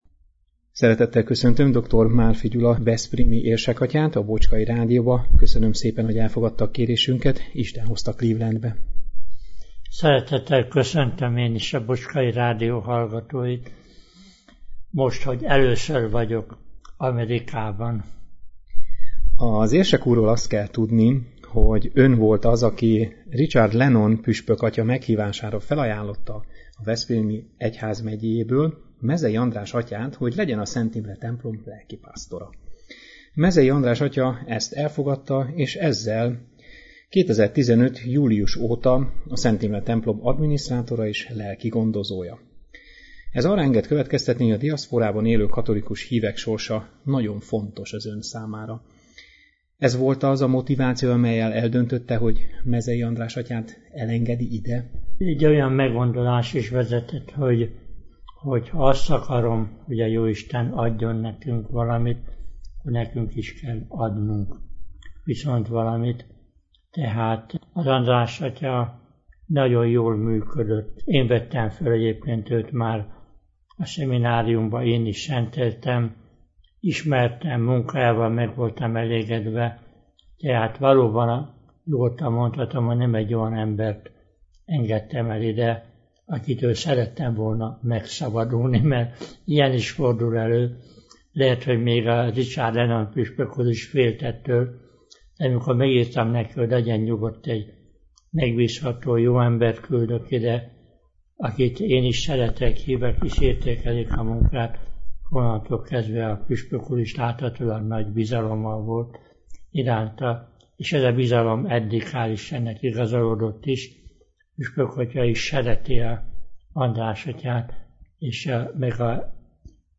2016. november 6-án ünnepi szentmisére került sor a Szent Imre templomban, hiszen ezen a napon volt az egyházközség búcsúja, melyet dr. Márfi Gyula veszprémi érsek is megtisztelt jelenlétével.